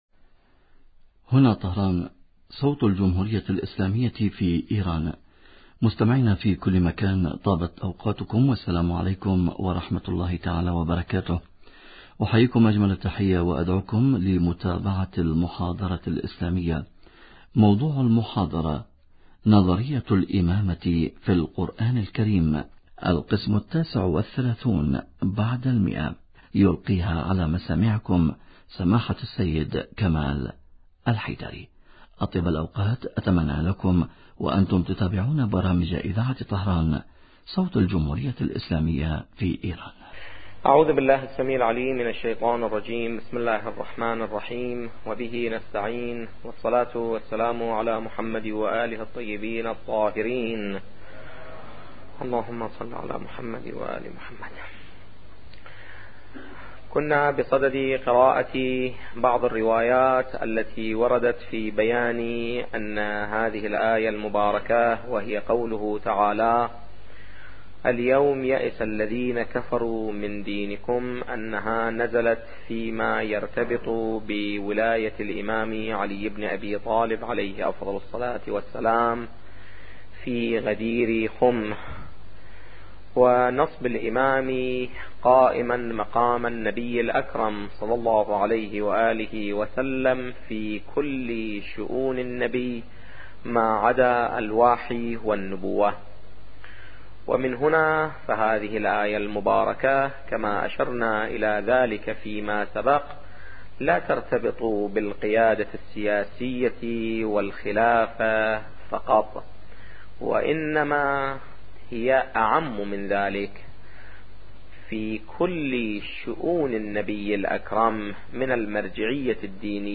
محاضرات أخرى